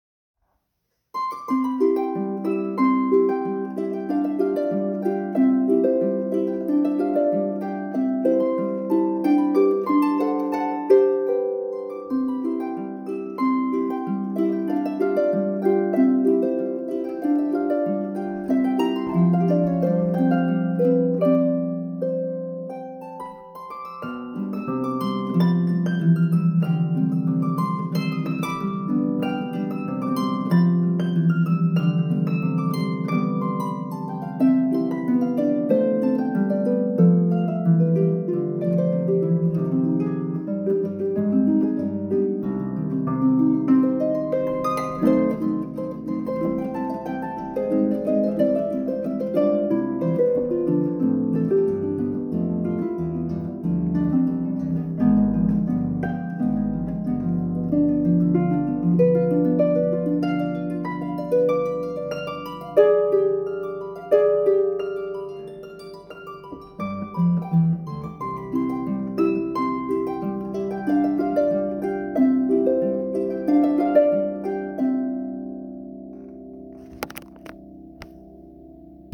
Pedal Harp
Advanced An original ragtime ditty based on...